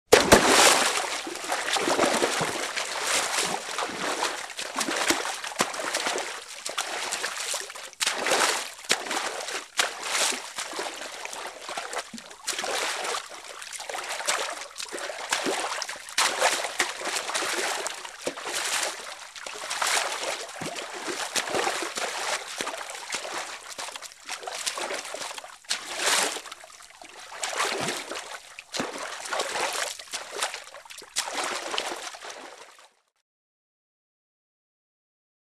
Pool, Swimming | Sneak On The Lot
Light Splashing And Swimming In A Pool, Close Up